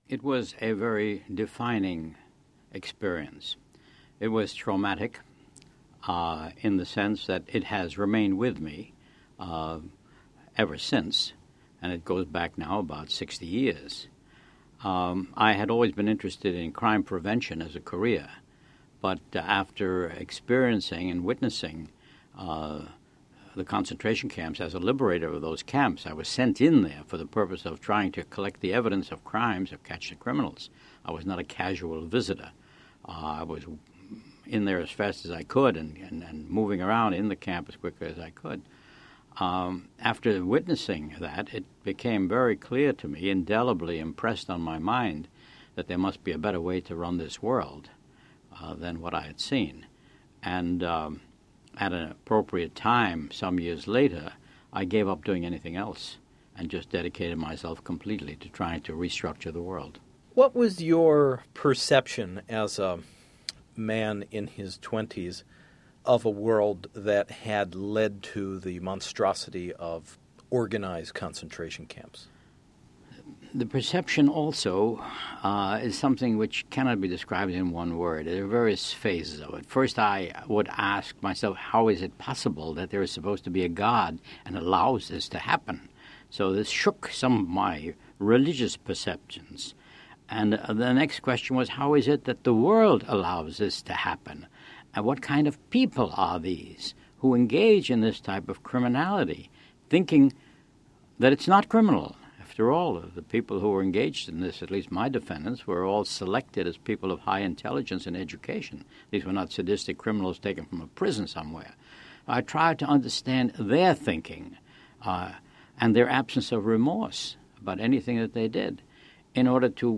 Meet one of the great legal minds as he expounds on his experience of collecting evidence at Nazi concentration camps for his subsequent prosecution of twenty-four war criminals (the photo above was taken of him as a young prosecutor at the Nuremberg trials). He also comments on the nature of human behavior, the education of young Americans, and his tireless pursuit — eventually realized — to establish the UN’s International Criminal Court.